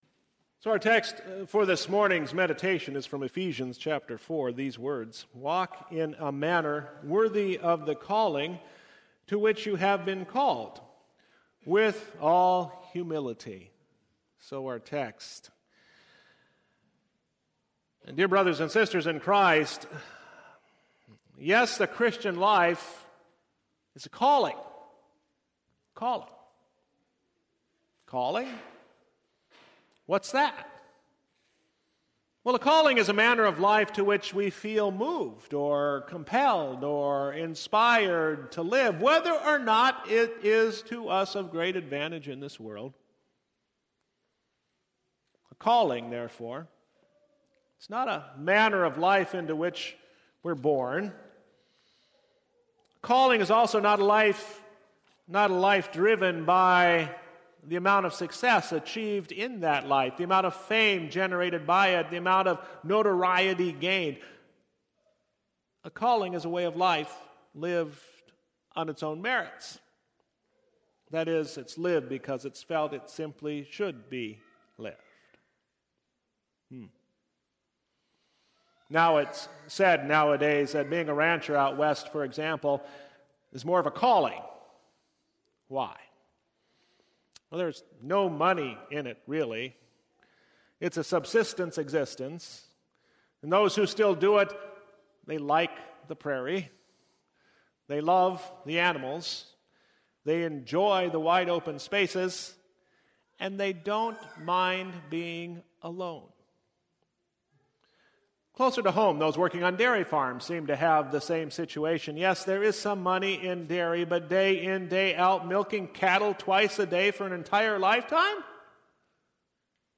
Service Type: Divine Service II